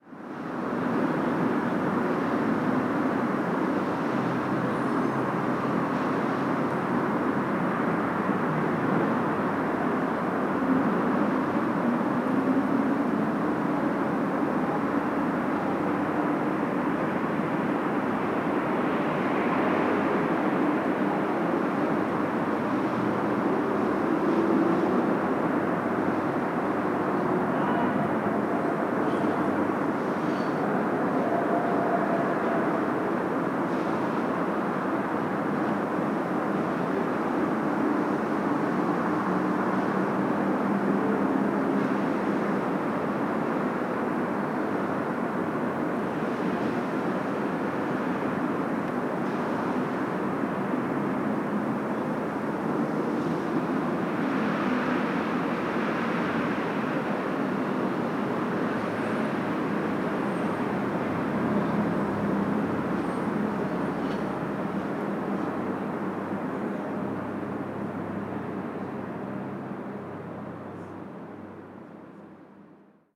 Fondo de tráfico de la ciudad de Nueva York, Estados Unidos